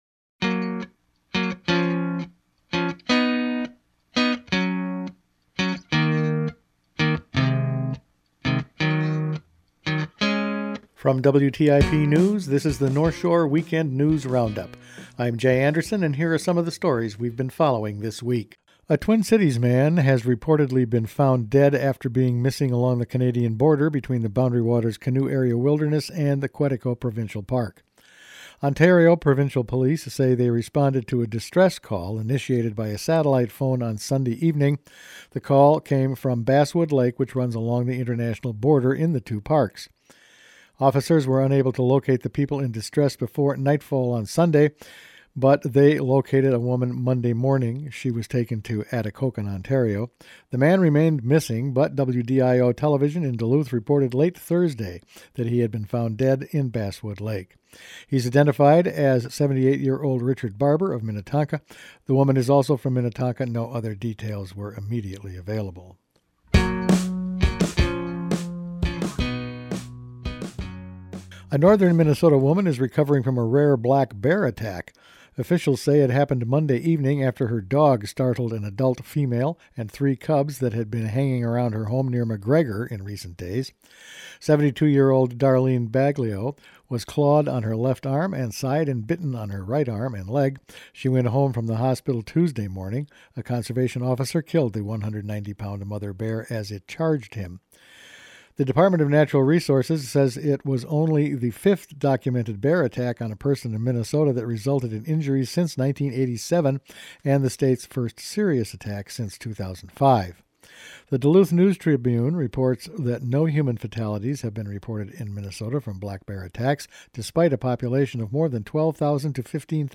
Each week the WTIP news staff puts together a roundup of the news over the past five days. A Boundary Waters death, a rare bear attack, more completed bike trails, mining samples start and a lot of salt and discovery of a mysterious wreck…all in this week’s news.